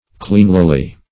cleanlily - definition of cleanlily - synonyms, pronunciation, spelling from Free Dictionary Search Result for " cleanlily" : The Collaborative International Dictionary of English v.0.48: Cleanlily \Clean"li*ly\, adv.
cleanlily.mp3